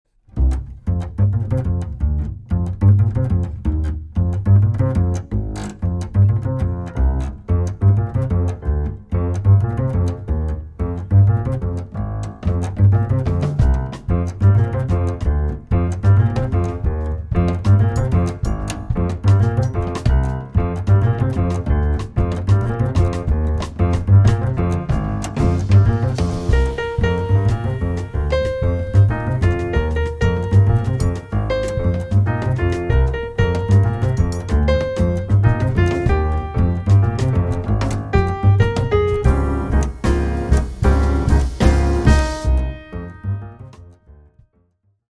Recorded on July 13.2025 at Studio Happiness